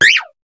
Cri de Bébécaille dans Pokémon HOME.